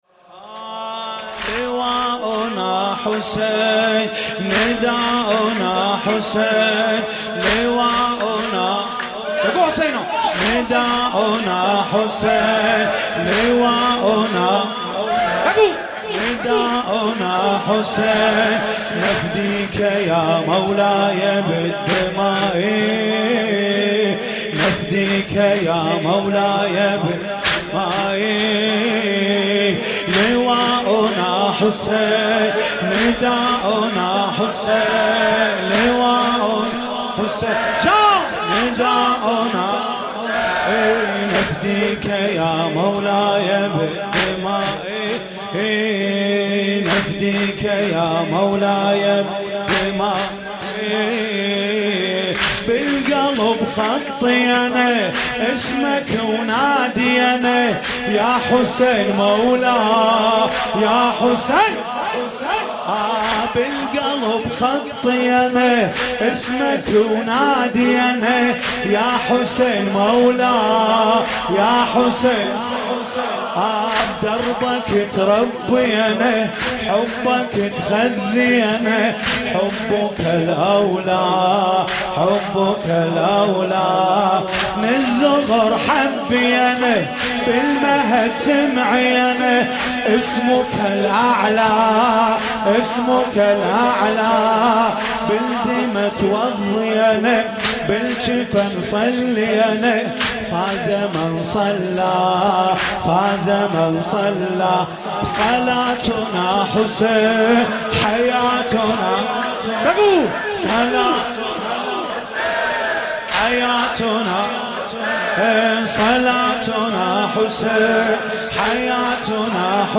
مناسبت : دهه اول صفر